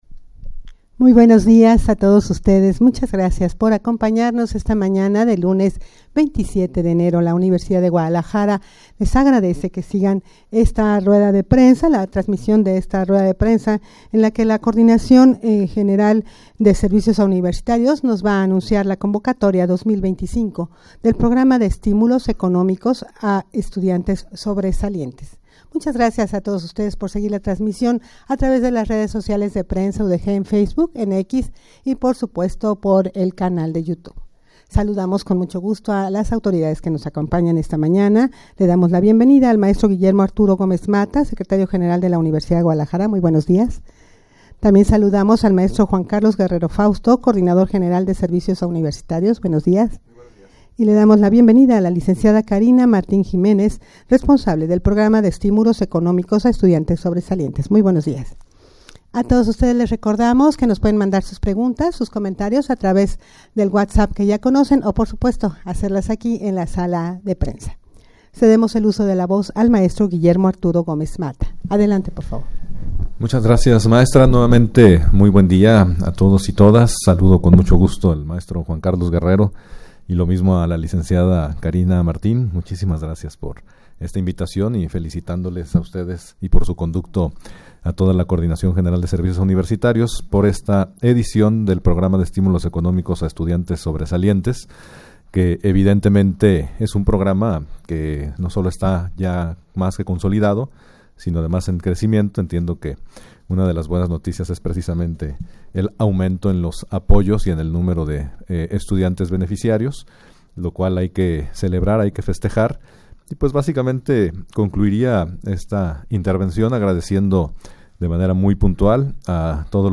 Audio de la Rueda de Prensa
rueda-de-prensa-convocatoria-2025-del-programa-de-estimulos-economicos-a-estudiantes-sobresalientes.mp3